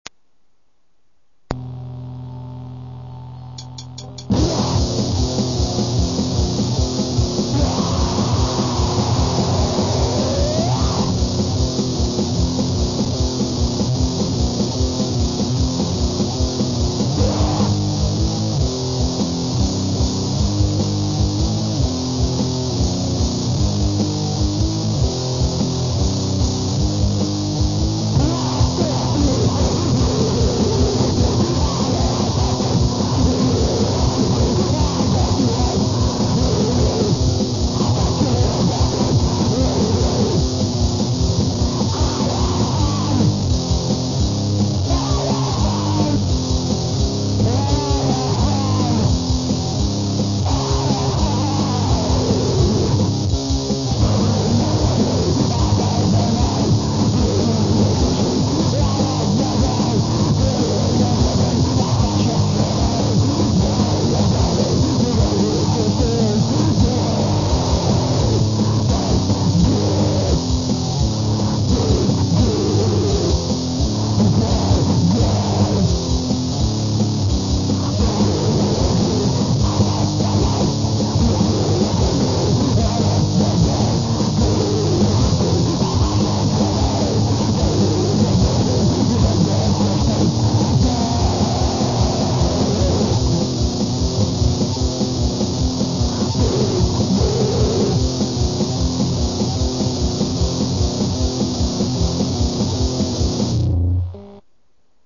Grind core